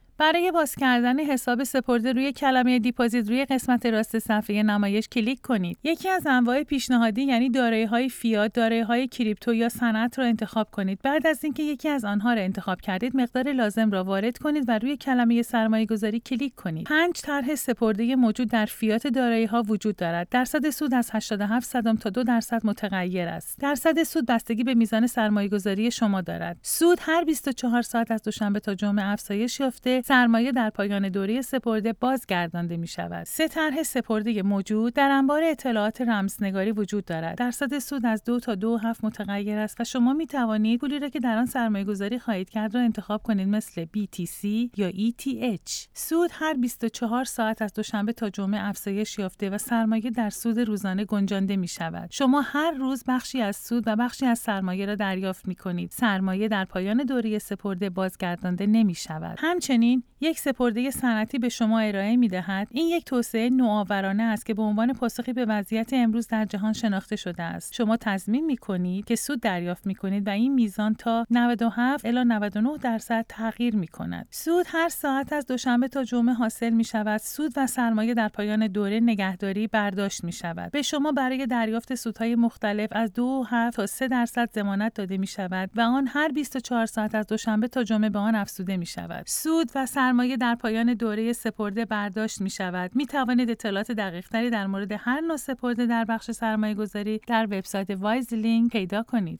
Female
Explanatory